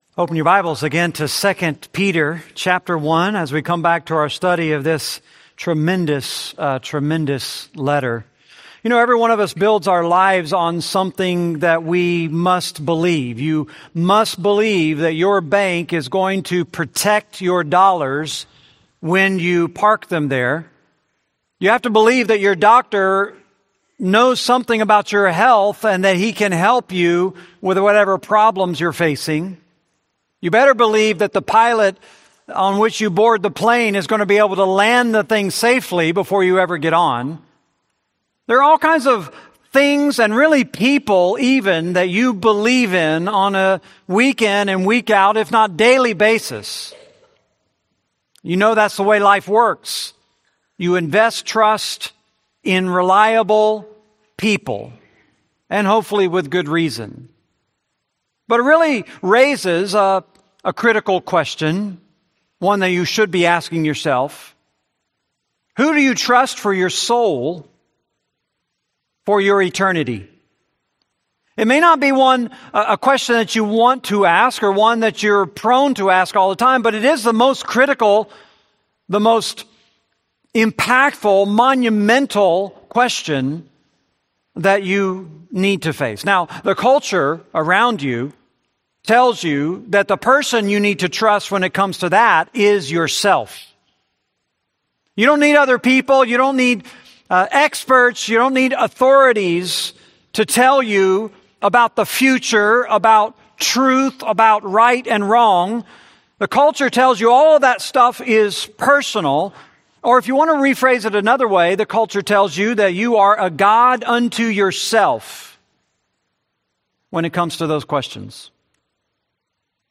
Series: 2 Peter, Sunday Sermons